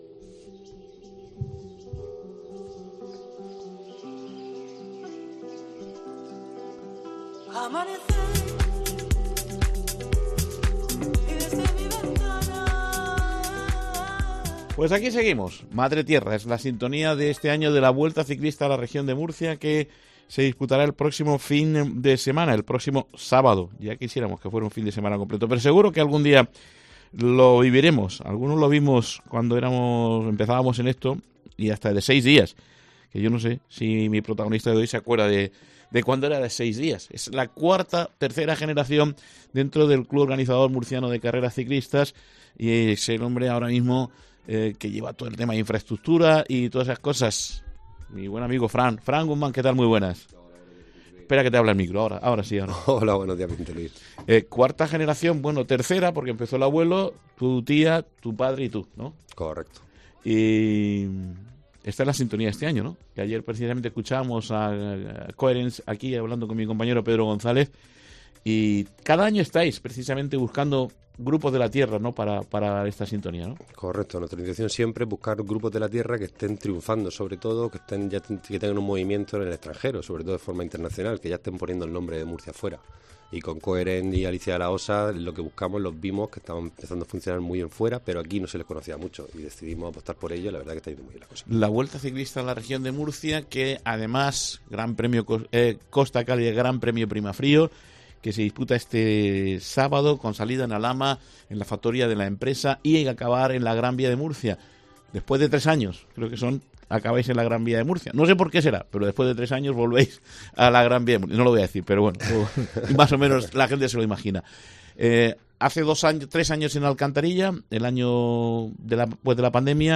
se ha pasado por los micrófonos de COPE indicando que " estamos en contacto continuo con la Guardia Civil.